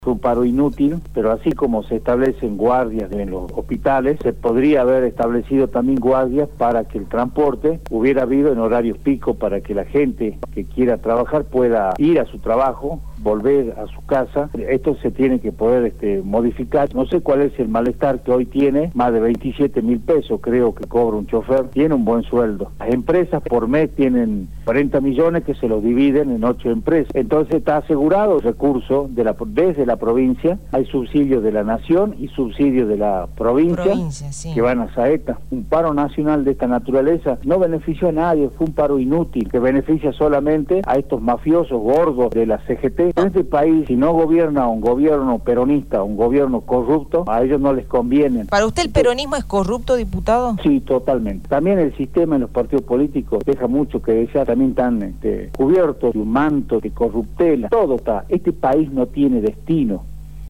Mario Ávalos, Diputado Provincial
¿Para usted el peronismo es corrupto diputado?